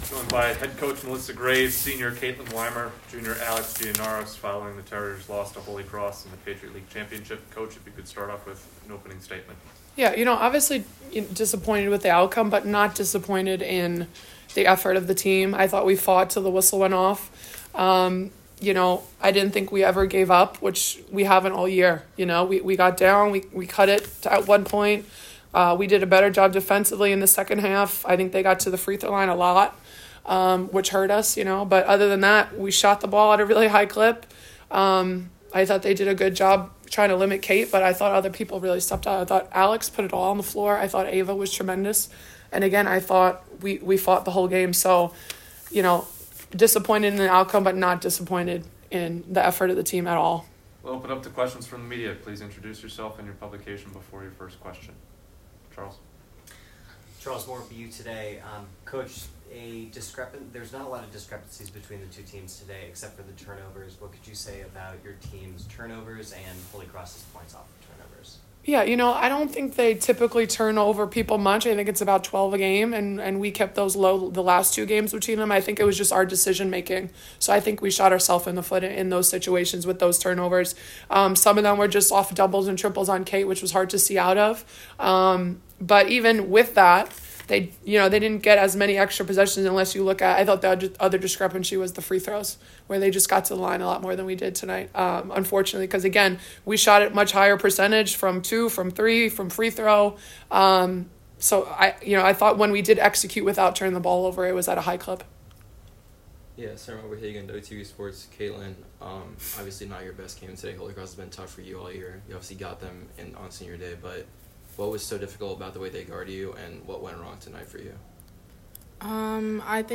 WBB_PL_Title_Postgame.mp3